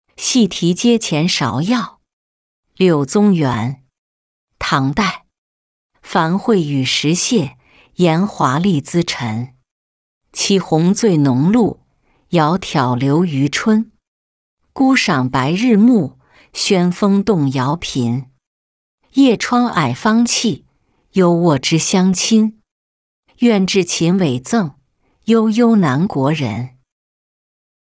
戏题阶前芍药-音频朗读